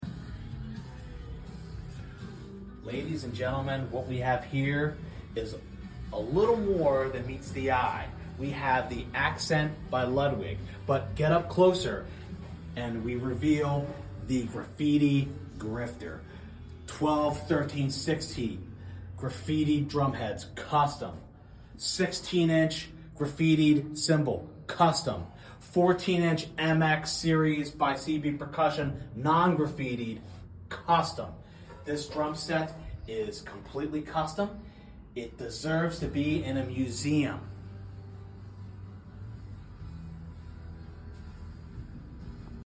The Graffiti Grifter Drum Set sound effects free download